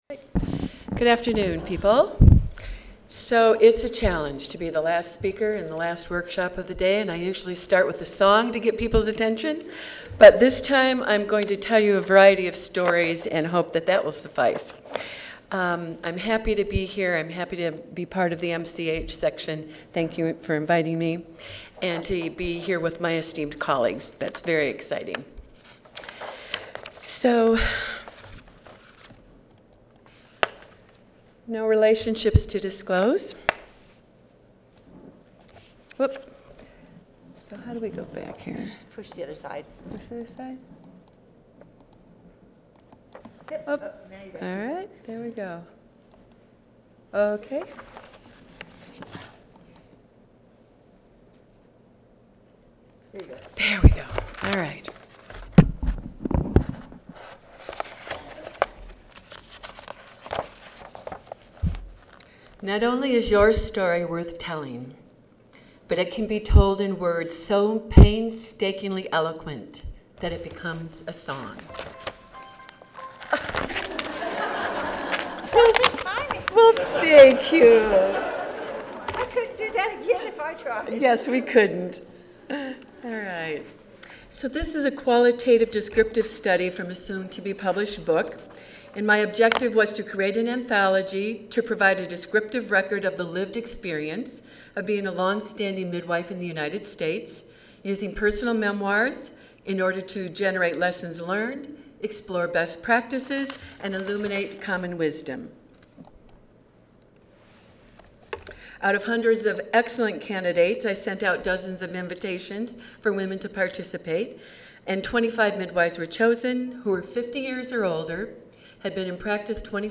3408.0 Social Justice at the Time of Birth: Improving Access and Quality of Maternity Services Monday, November 8, 2010: 4:30 PM - 6:00 PM Oral This session will present an overview of new studies that make a significant contribution to the weight of evidence supporting the safety of planned home birth with midwives.